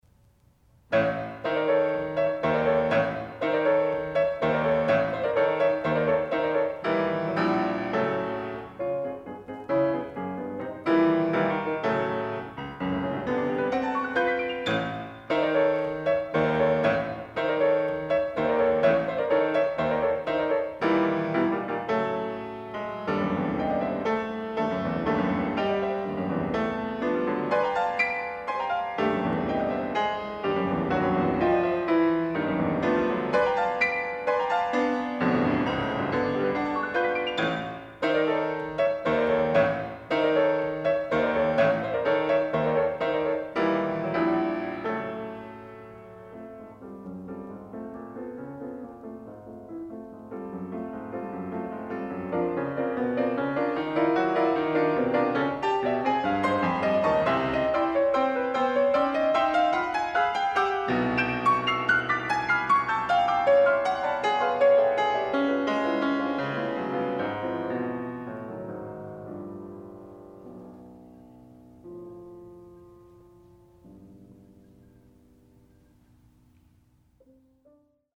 Genreperformed music
Additional Date(s)Recorded October 6, 1969 in the Ed Landreth Hall, Texas Christian University, Fort Worth, Texas
Sonatas (Piano)
Short audio samples from performance